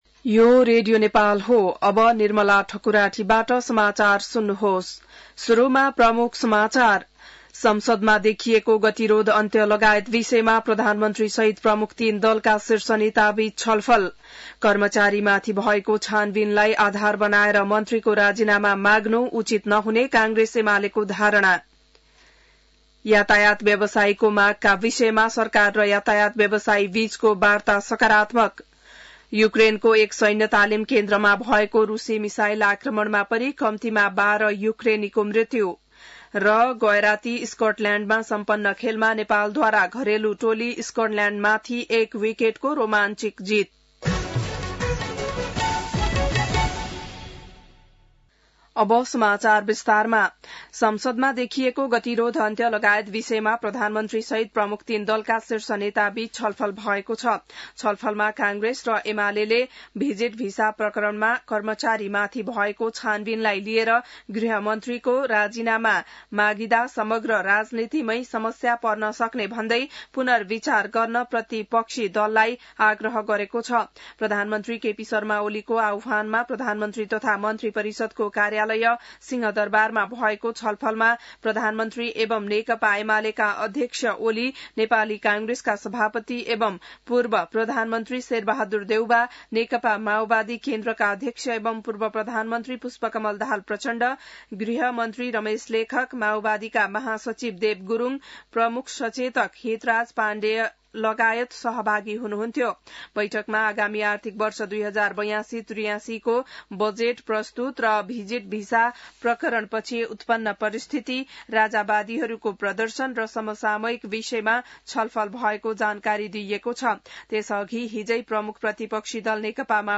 बिहान ९ बजेको नेपाली समाचार : २० जेठ , २०८२